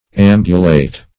Search Result for " ambulate" : Wordnet 3.0 VERB (1) 1. walk about ; not be bedridden or incapable of walking ; The Collaborative International Dictionary of English v.0.48: Ambulate \Am"bu*late\, v. i. [L. ambulare to walk.